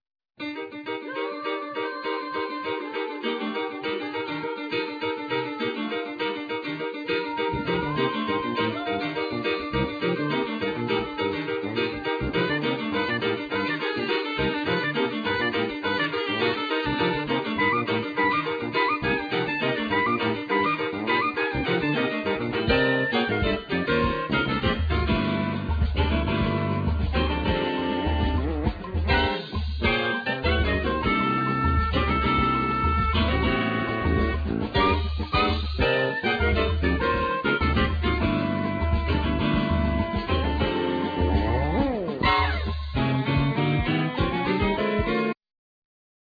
Piano
Keyboards
Soprano Saxophne
Vocal
Bass
Drums
Percussion